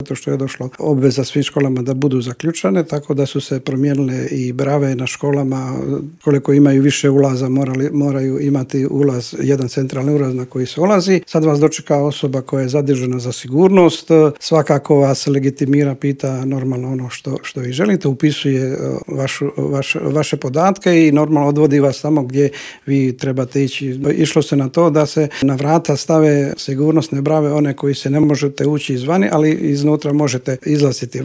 Teme su to o kojima smo u Intervjuu Media servisa razgovarali s ravnateljem Uprave za potporu i unaprjeđenje sustava odgoja i obrazovanja u Ministarstvu obrazovanja Momirom Karinom.